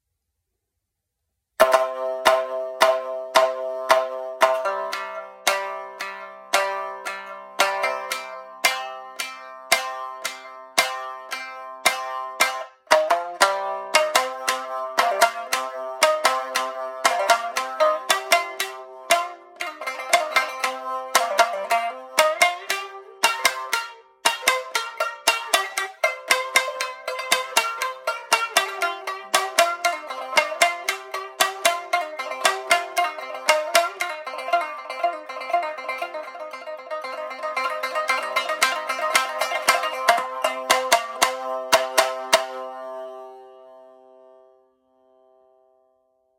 今回は自分の演奏を録音し、ブログの中で聴けるようにしてみたので、時間がありましたら三味線の音も聴きながらブログを読んでいただけると嬉しいです。
ちゃんとした録音機材などを持っているわけではないので、音質はあまりよくないかもしれませんが、ご了承ください。
新旧節は、徐々にパフォーマンス性が高くなり複雑になっていく新節に、昔のより民謡らしい雰囲気を合わせたような、どこかゆったりと聴こえるリズムが特徴的な曲です。